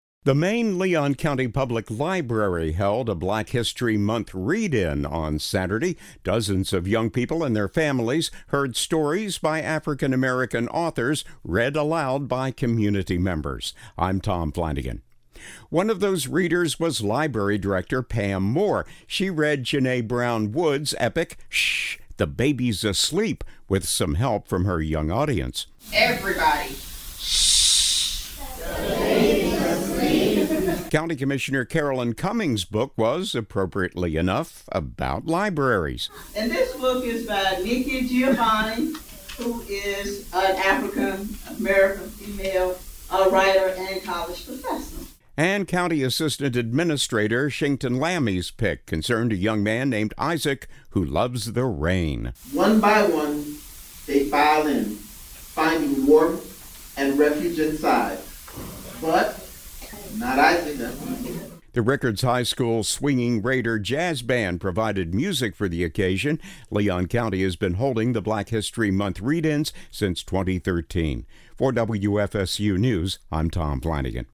The main Leon County Public Library held a Black History Month Read-In on Saturday. Dozens of young people and their families heard stories by African-American authors read aloud by community members.
Leon County has been holding the Black History Month Read-Ins since 2013.